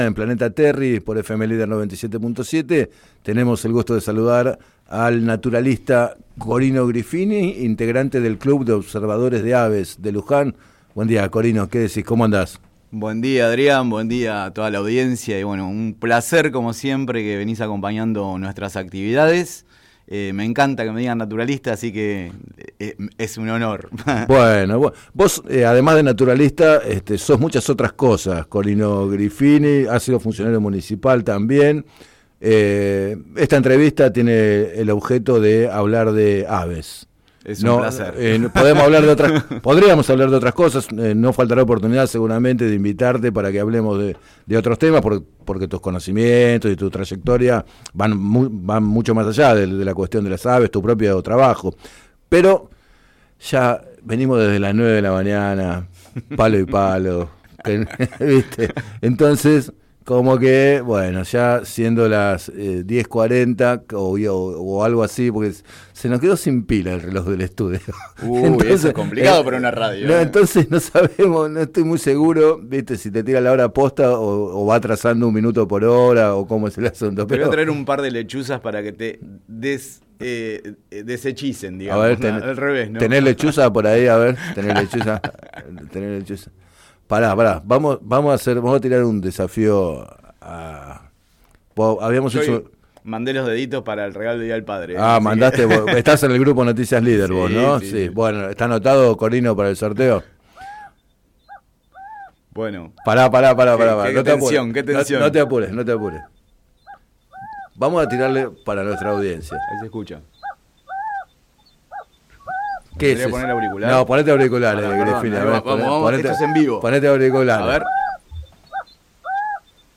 En declaraciones al programa Planeta Terri de FM Líder 97.7